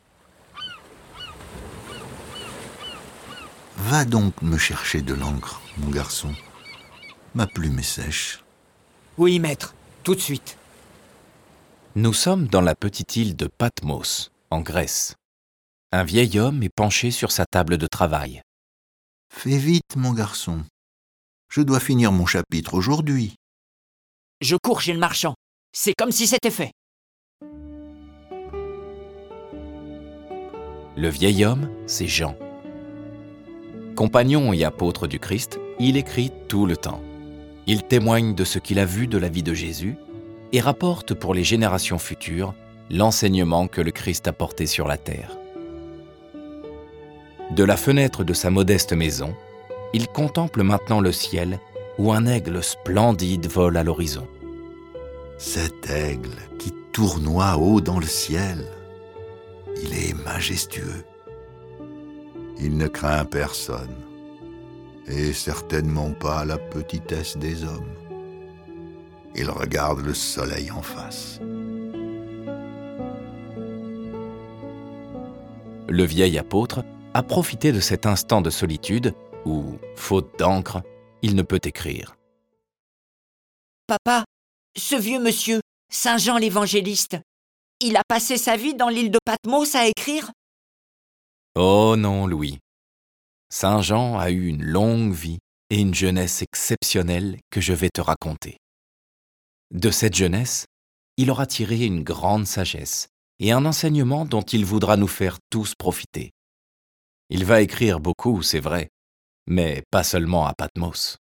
Retrouvez la vie exceptionnelle du disciple préféré de Jésus qui meurt presque centenaire. Cette version sonore de ce récit est animée par dix voix et accompagnée de plus de trente morceaux de musique classique.
Le récit et les dialogues sont illustrés avec les musiques de Bach, Beethoven, Debussy, Donizetti, Dvorak, Grieg, Haendel, Mahler, Mendelssohn, Mozart, Pergolese, Schubert, Tchaikovski, Telemann et Vivaldi.